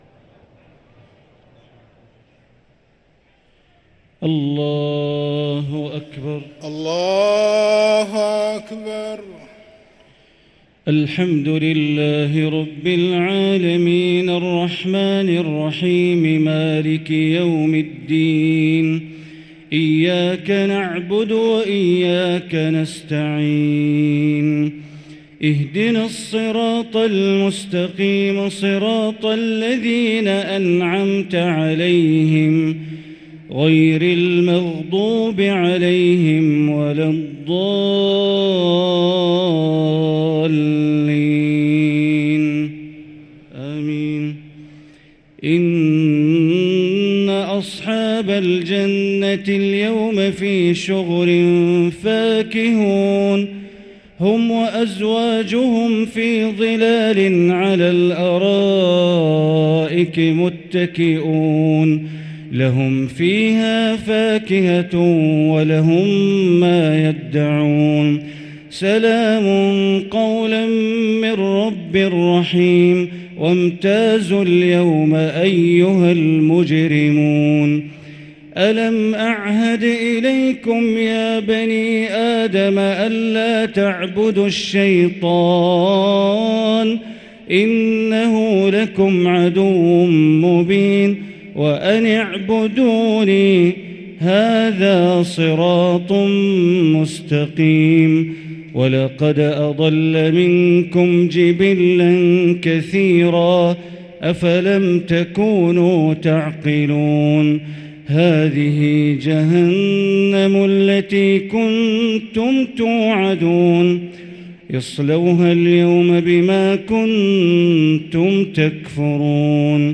صلاة التراويح ليلة 25 رمضان 1444 للقارئ بندر بليلة - التسليمتان الأخيرتان صلاة التراويح